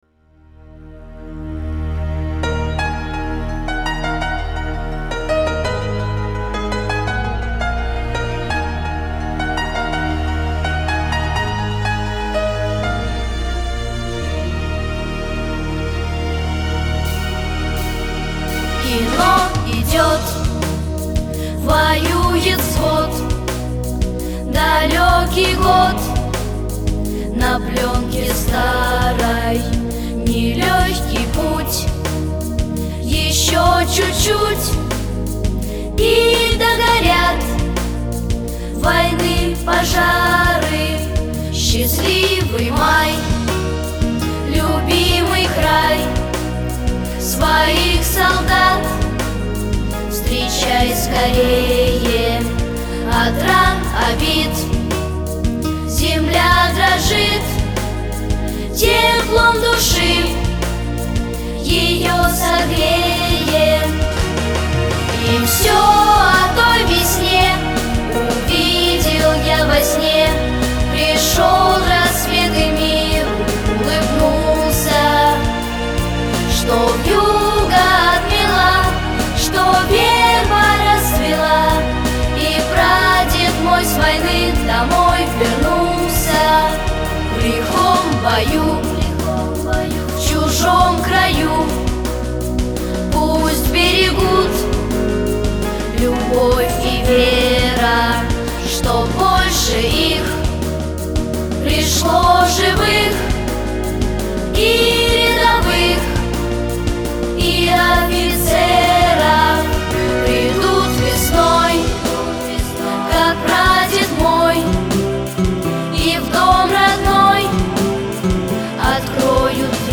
Для Вас звучит песня
в исполнении детей.